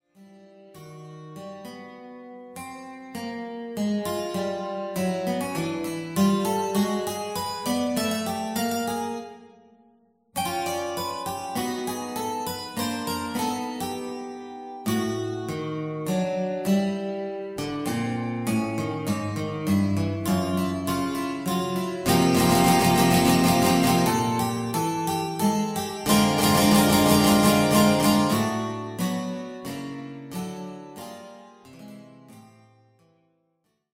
Classical Guitar
First performance